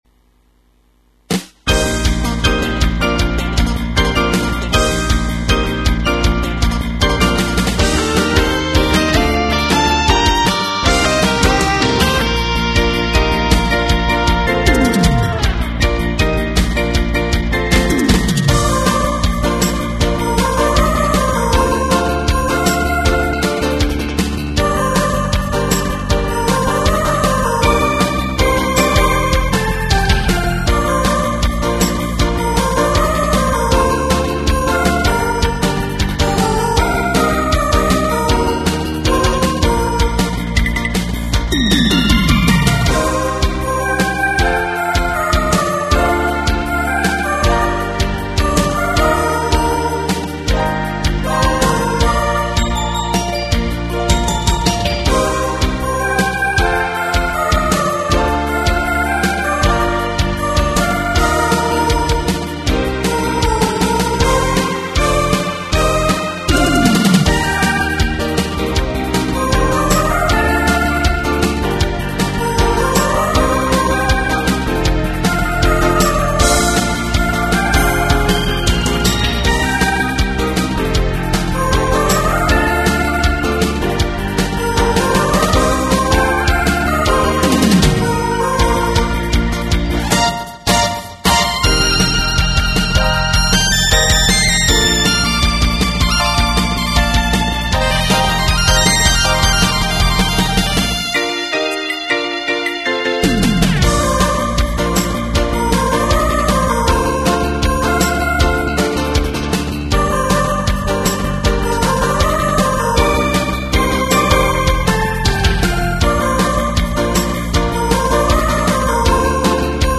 ラブリー（笑）な曲ですね。
耳コピのMIDIやらアレンジものです。
念のため音質は落としてます。